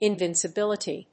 音節in・vin・ci・bil・i・ty 発音記号・読み方
/ìnvìnsəbíləṭi(米国英語), ˌɪˌnvɪnsʌˈbɪlɪti:(英国英語)/